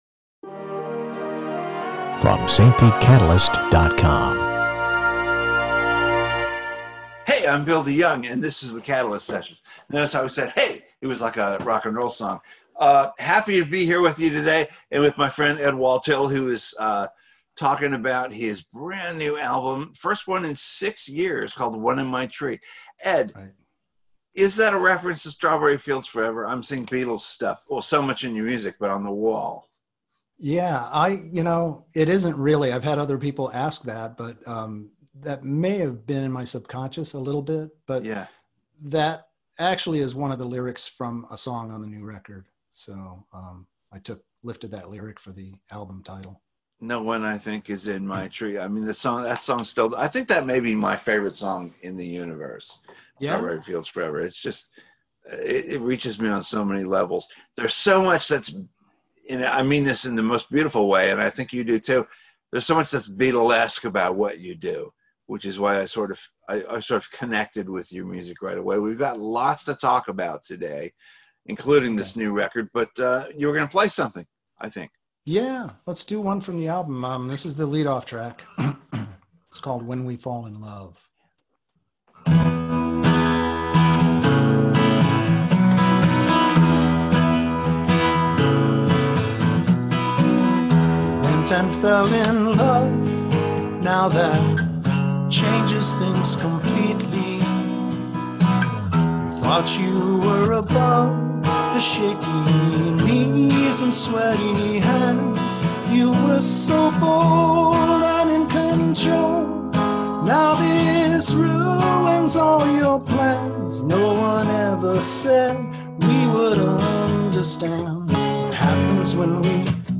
in the episode, he and the host discover they’re both non-apologetic Beatle geeks.